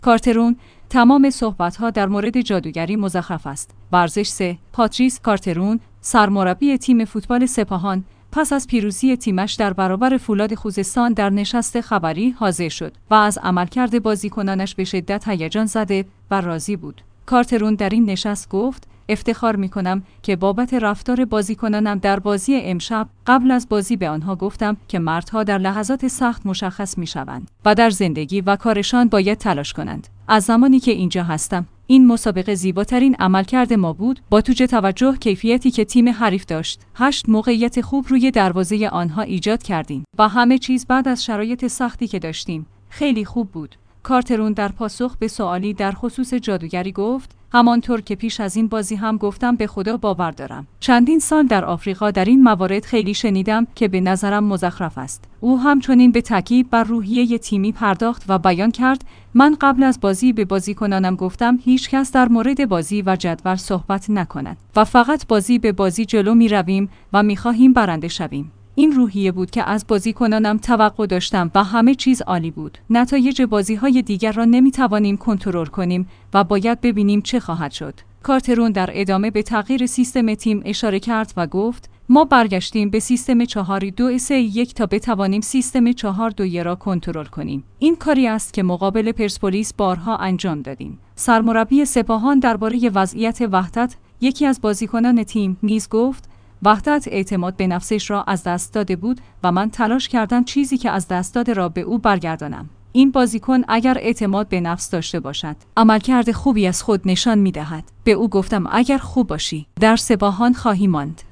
ورزش 3/پاتریس کارترون، سرمربی تیم فوتبال سپاهان، پس از پیروزی تیمش در برابر فولاد خوزستان در نشست خبری حاضر شد و از عملکرد بازیکنانش به شدت هیجان‌زده و راضی بود.